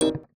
UIClick_Smooth Tone Metallic 01.wav